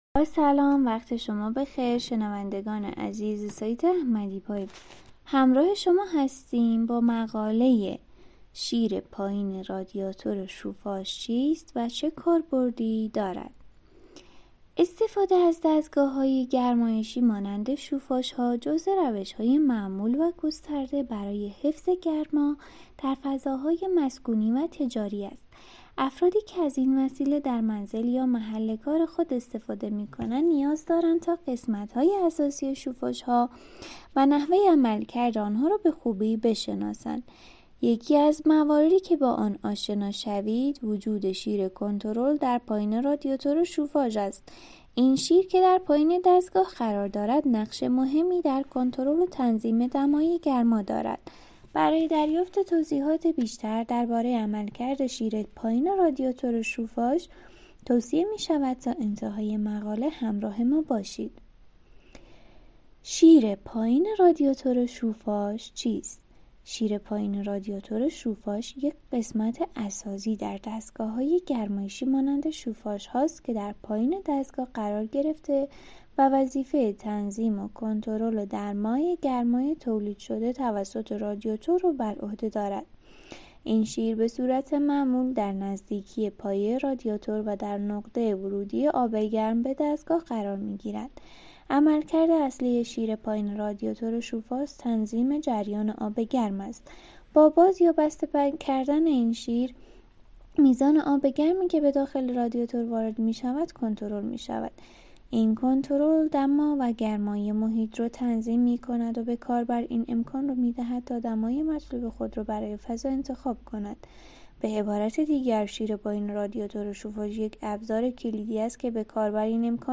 تمامی متن مقاله شیر پایین رادیاتور شوفاژ چیست و چه کاربردی دارد را می توانید به صورت pdf و در قالب یک پادکست گوش دهید.